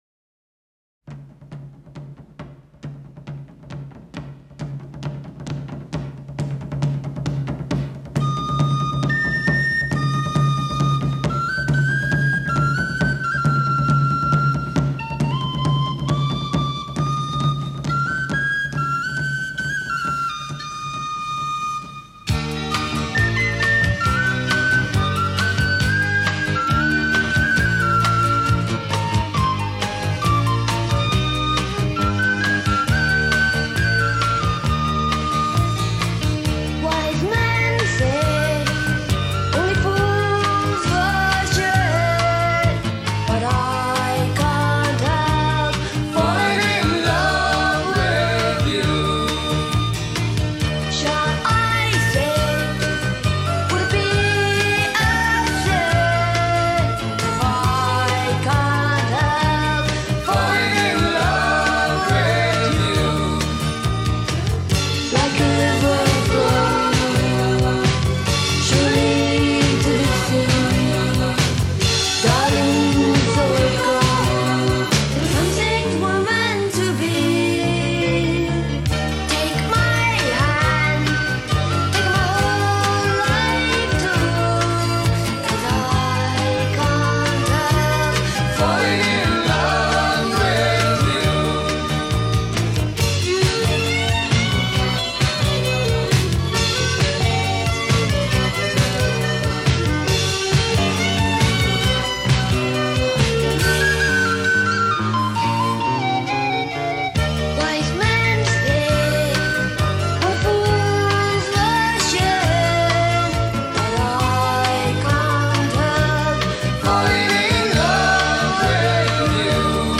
a London-based Celtic band that had a